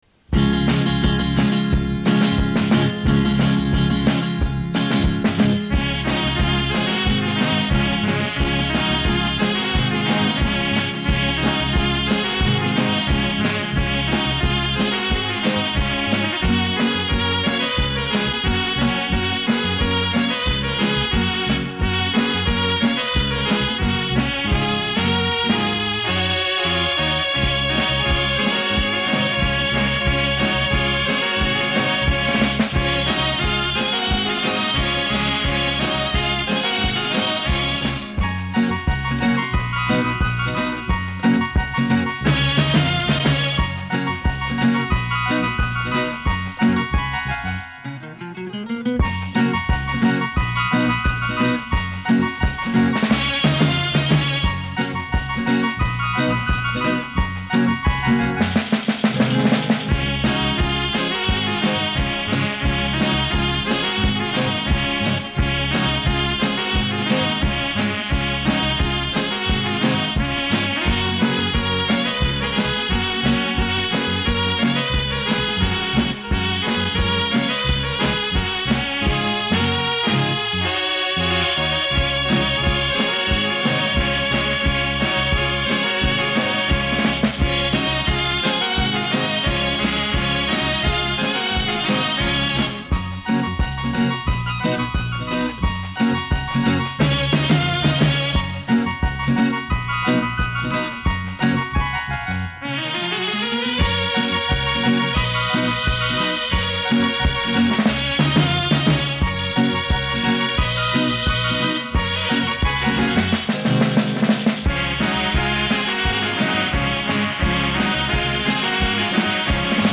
浪漫的电子琴小品，感受火爆的拉美风情。
这盘盒带已经收藏37年了，属绝对的老古董；脱磁严重，
音质不是很好，数字化录转，有利于大家保存和欣赏。